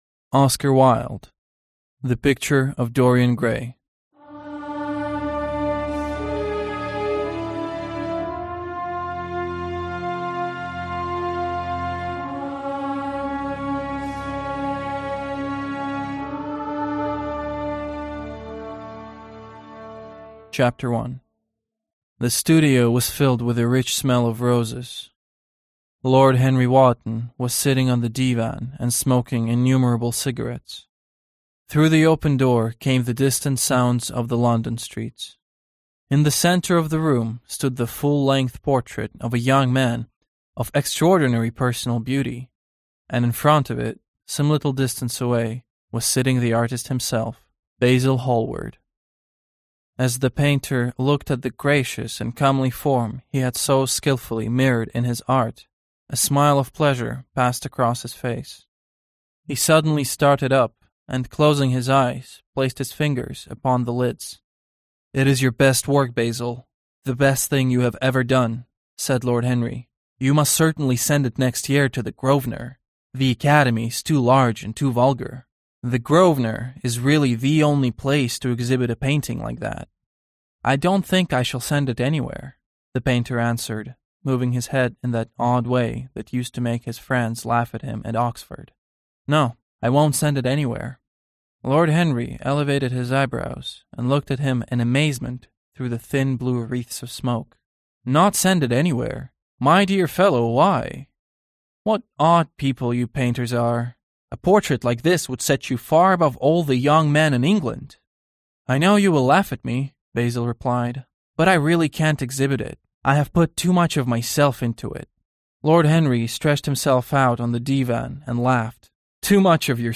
Аудиокнига Портрет Дориана Грея / The Picture of Dorian Gray | Библиотека аудиокниг